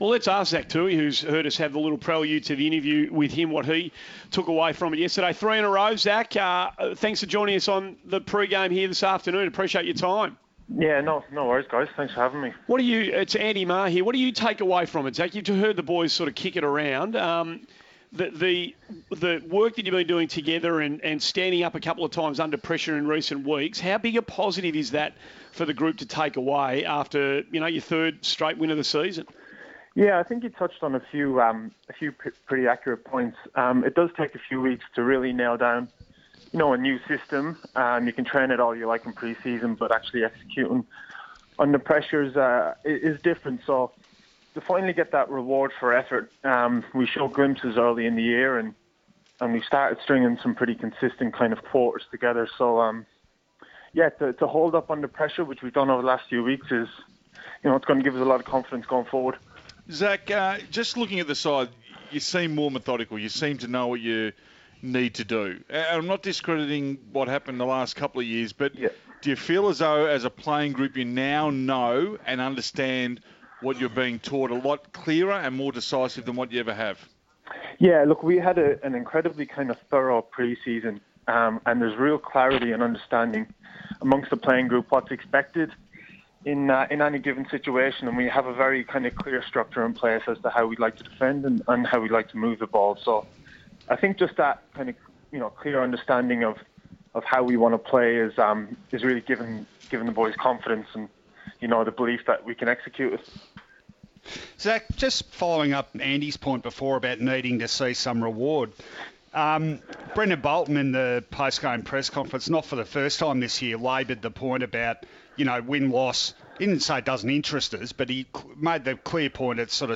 Carlton defender Zach Tuohy catches up with SEN 1116 after the Blues' Round 7 win over Collingwood.